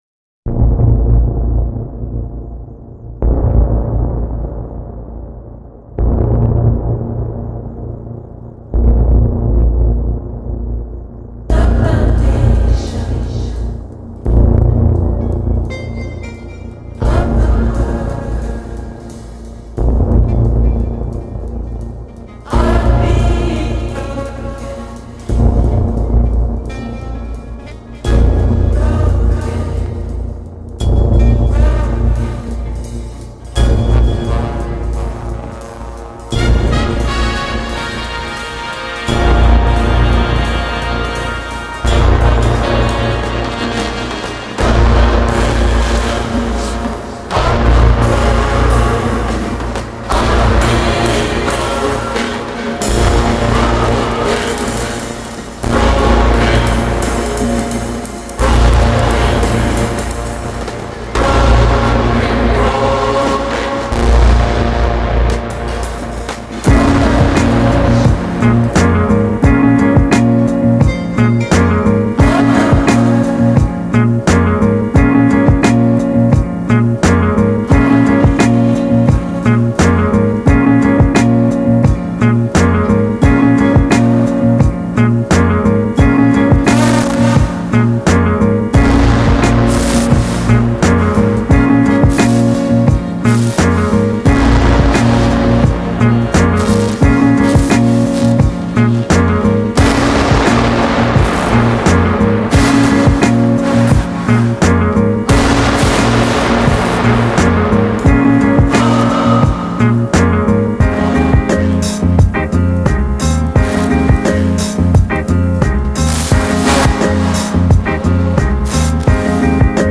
موسیقی الکترونیک موسیقی ار اند بی